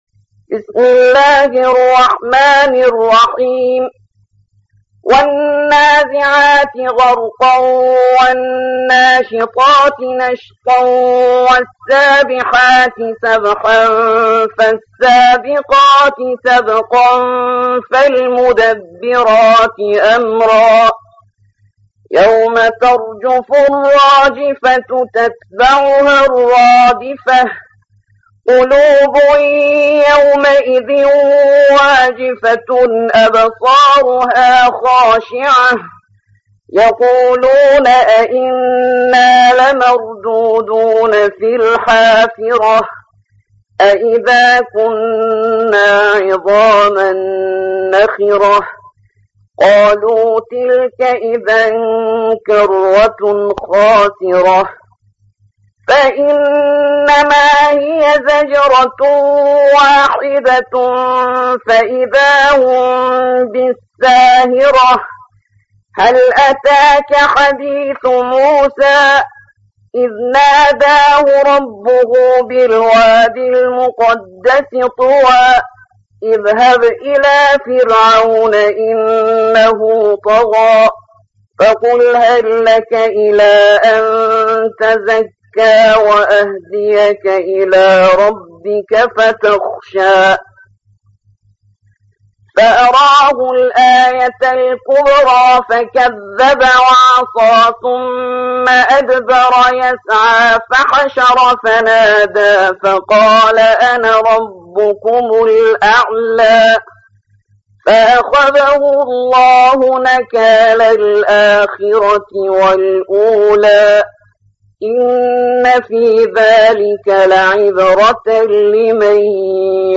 79. سورة النازعات / القارئ